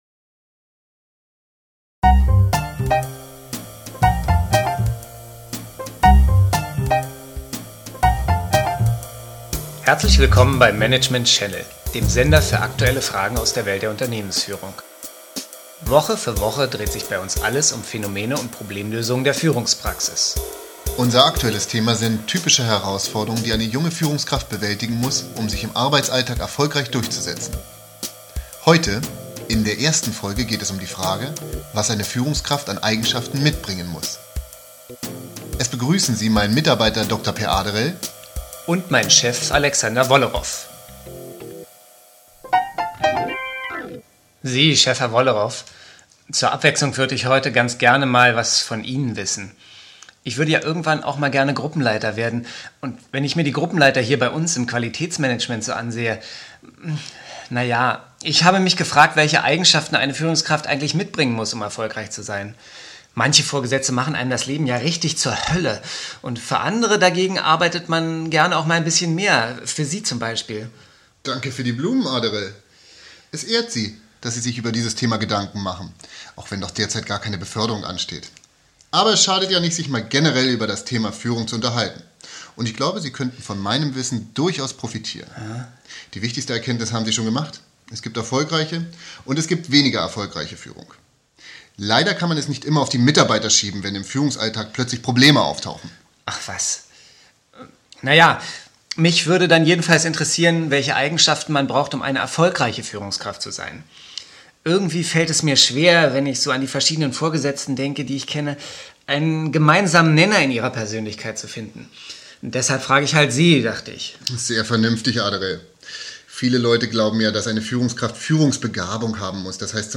Abteilungsleiter Wollerof und sein Assistent Dr. Peer Aderel beraten, welche Eigenschaften eine erfolgreiche Führungskraft haben sollte.